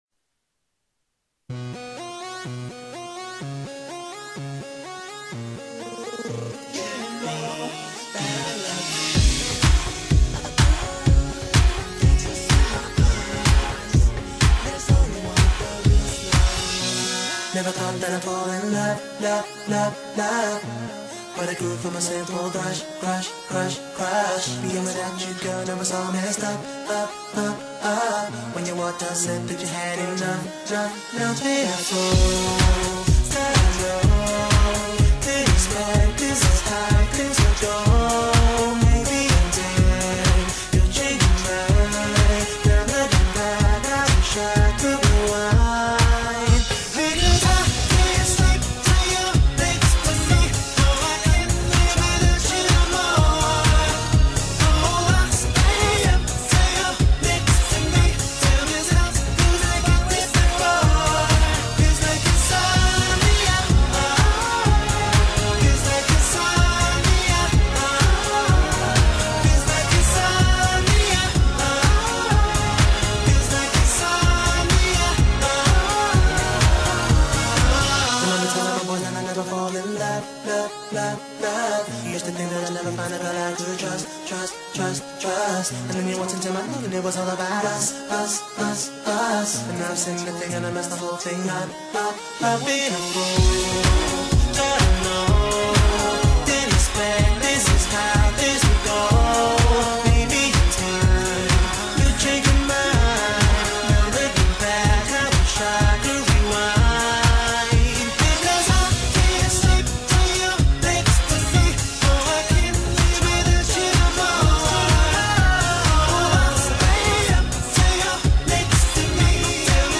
들을때는 꼭 양쪽다 들리는 헤드셋 또는 이어폰으로 들어주세요